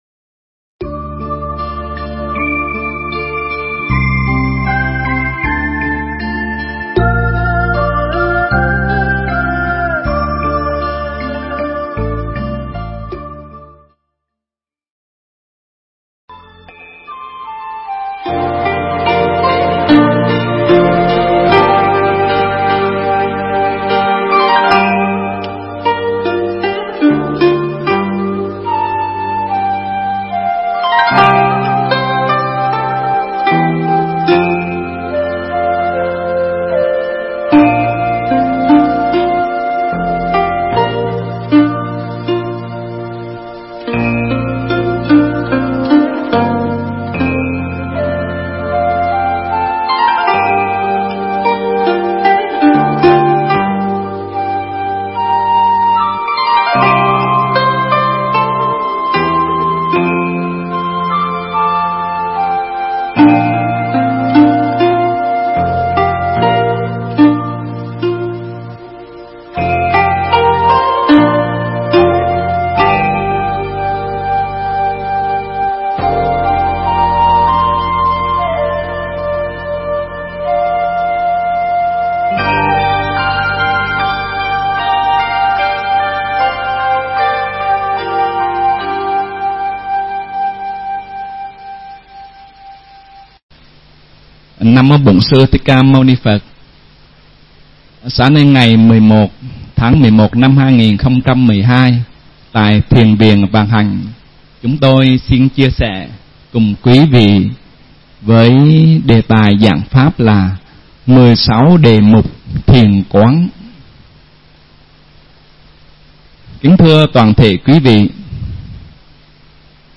Nghe Mp3 thuyết pháp 16 Đề Mục Thiền Quán